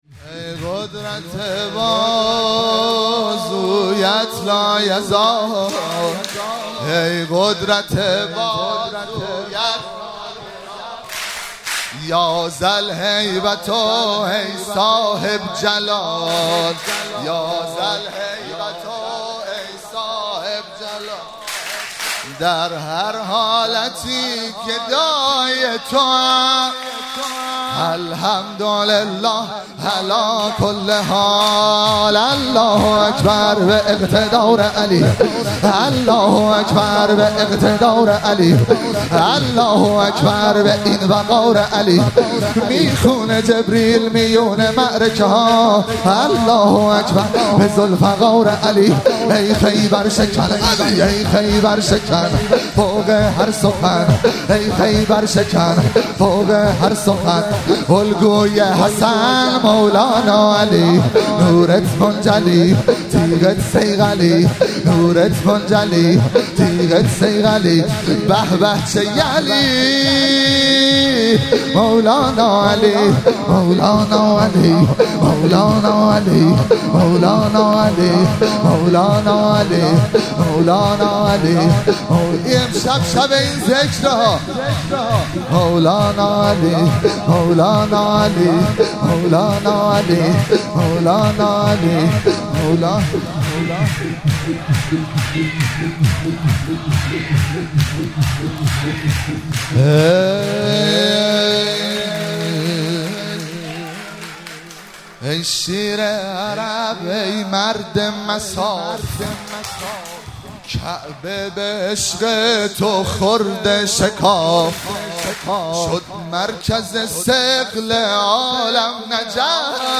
شور- ای قدرت بازویت لایزال
مراسم جشن شب دوم ویژه برنامه عید سعید غدیر خم 1444